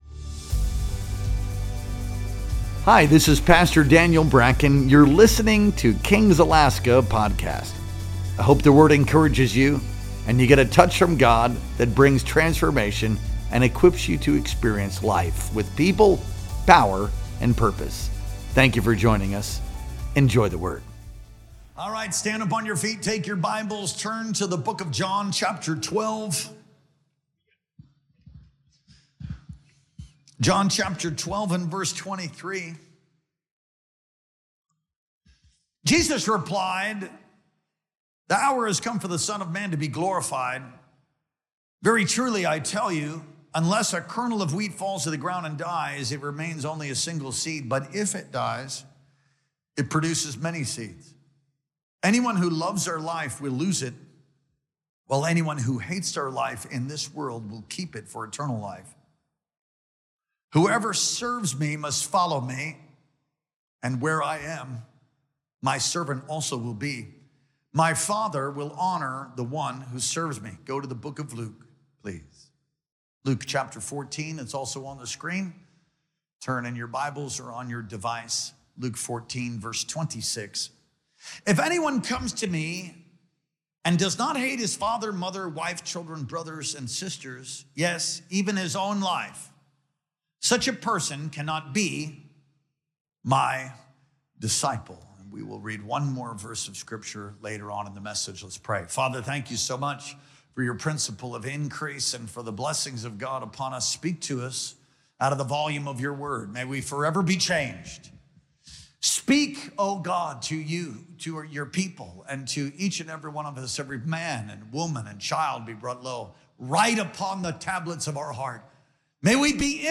Our Sunday Morning Worship Experience streamed live on August 3rd, 2025.